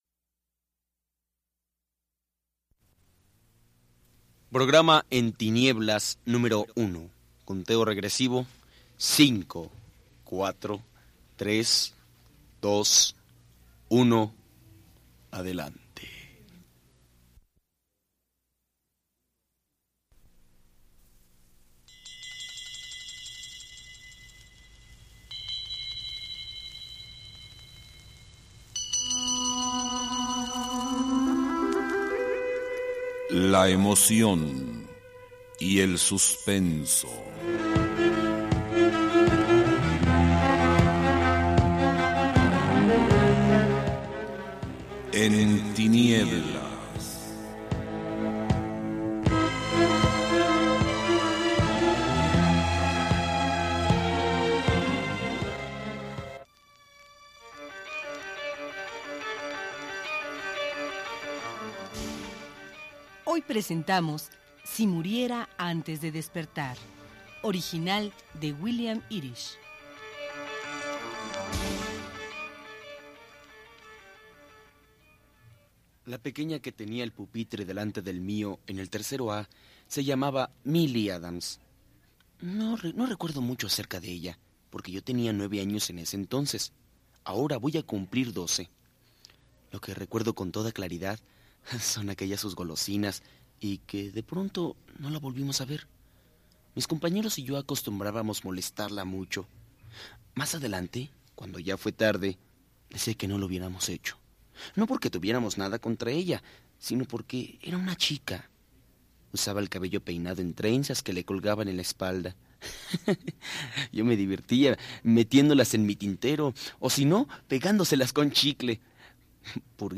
La serie En tinieblas de Radio IMER (Emitido de 1985 a 1989), presenta los mejores relatos de suspenso, de autores consagrados en el género como Edgar Allan Poe, Howard Phillips Lovecraft, Alejandro Dumas, así como Elena Garro y Carlos Fuentes, mediante adaptaciones radiofónicas.